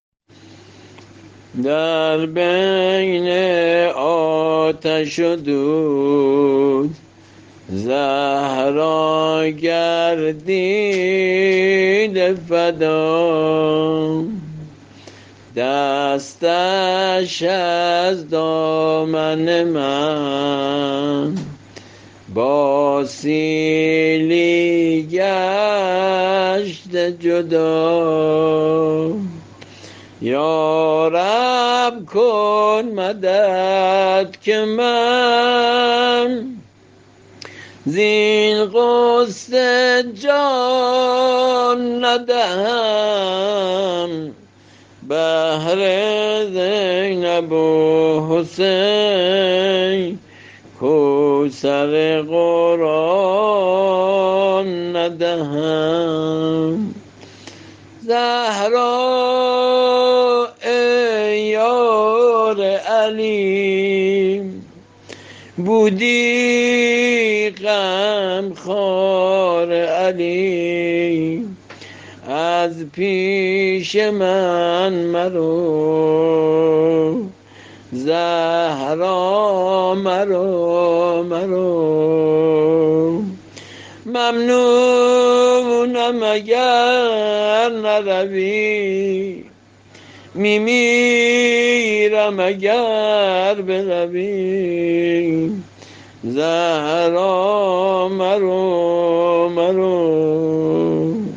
◾زمزمه‌ی فاطمیه